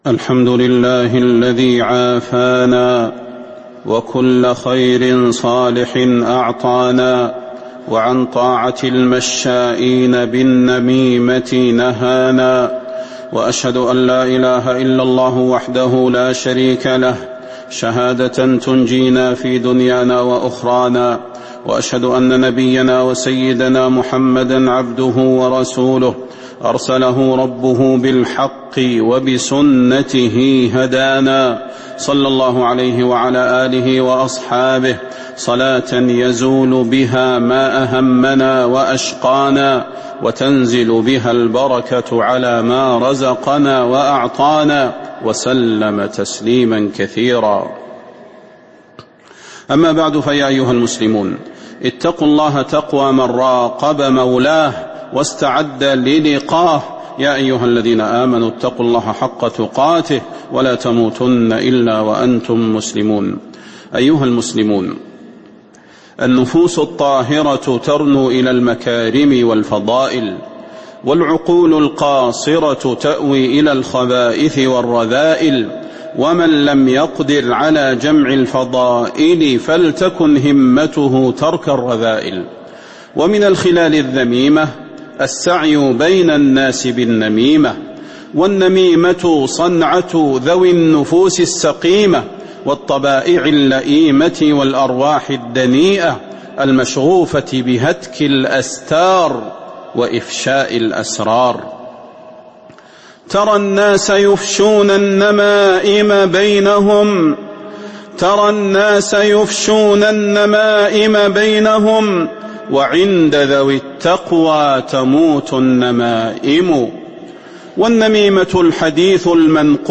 تاريخ النشر ٢٢ شوال ١٤٤٤ هـ المكان: المسجد النبوي الشيخ: فضيلة الشيخ د. صلاح بن محمد البدير فضيلة الشيخ د. صلاح بن محمد البدير النميمة جسر الشر